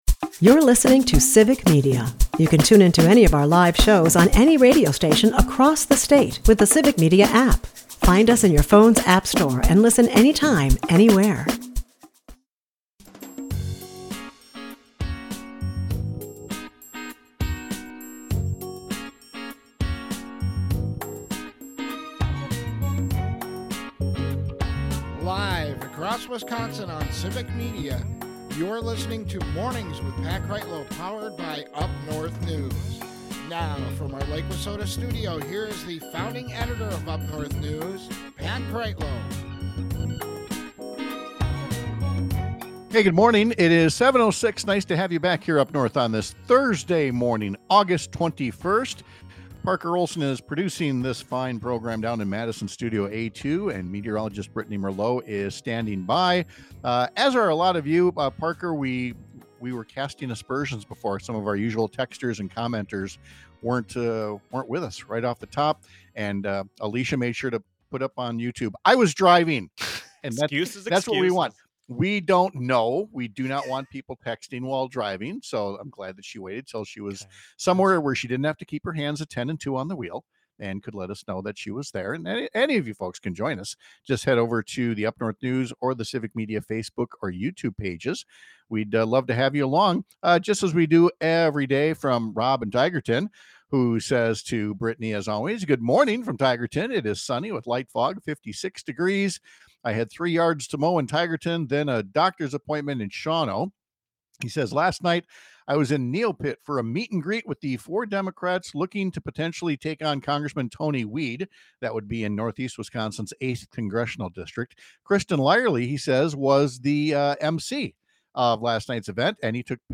Sarah Godlewski, the current secretary of state and former state treasurer, announced her candidacy Wednesday to grab a third constitutional position in as many elections. We’ll talk to her live about her decision and her platform.